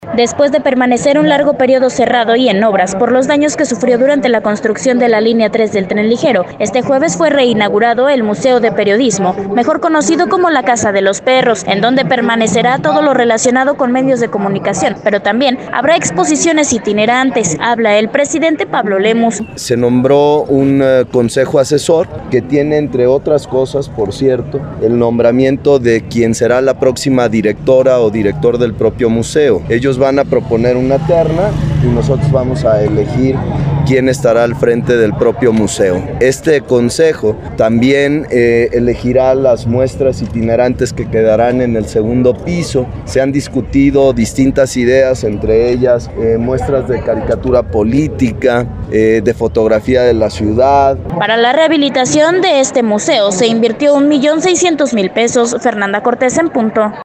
Después de permanecer un largo periodo cerrado y en obras, por los daños que sufrió durante la construcción de la Línea Tres del Tren Ligero, este jueves fue reinaugurado el Museo de Periodismo, mejor conocido como La casa de los perros, en donde permanecerá todo lo relacionado con medios de comunicación, pero también habrá exposiciones itinerantes. Habla el presidente Pablo Lemus: